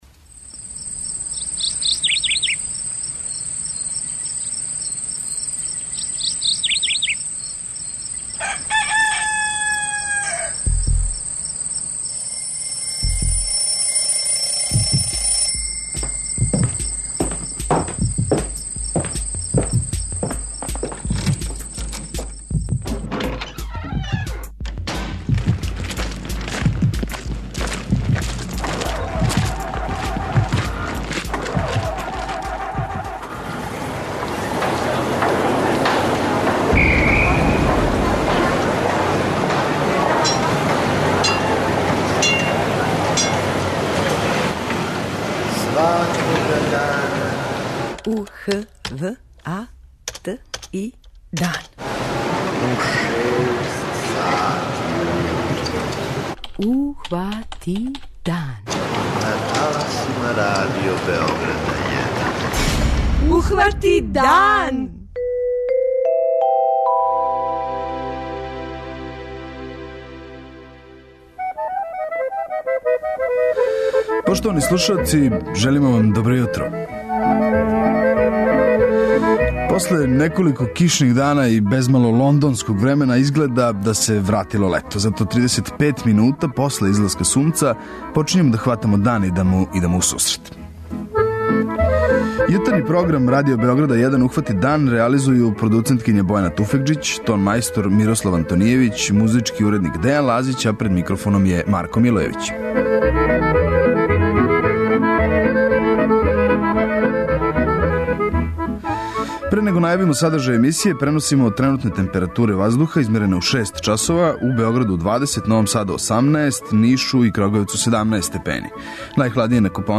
Радио Београд 1